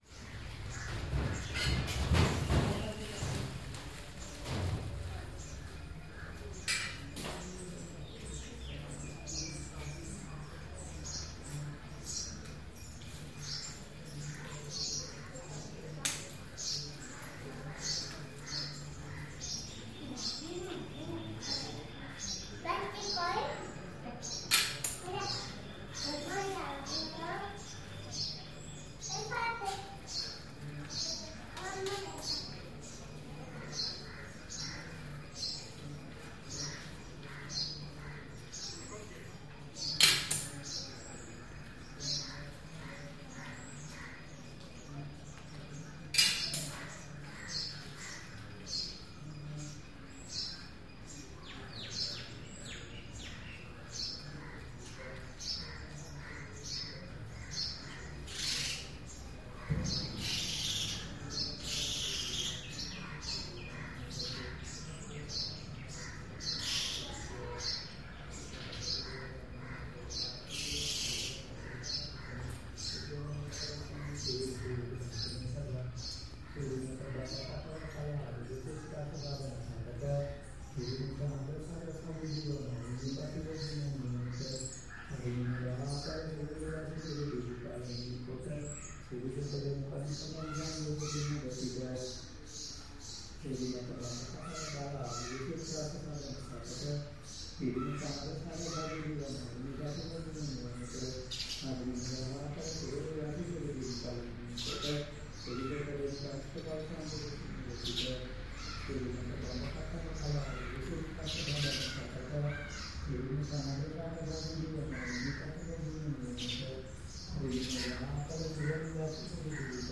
Tooth Temple - prayers and elephant bath